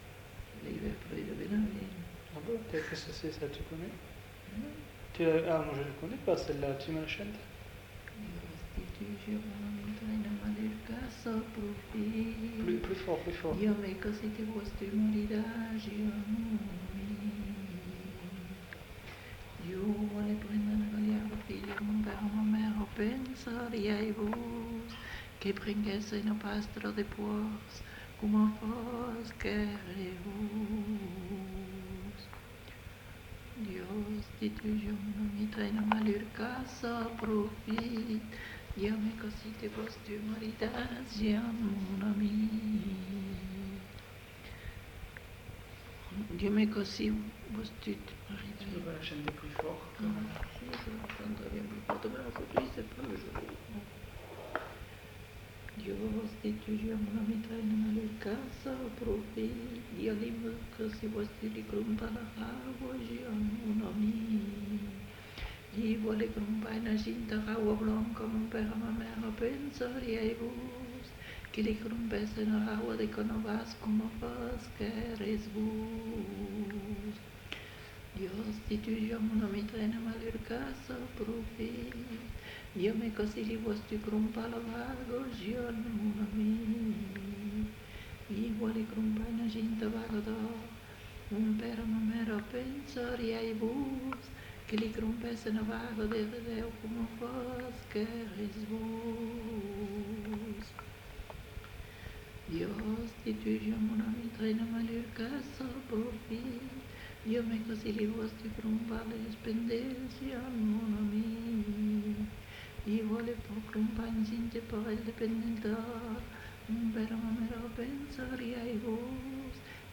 Genre : conte-légende-récit
Effectif : 1
Type de voix : voix de femme
Production du son : chanté
Classification : parodie du sacré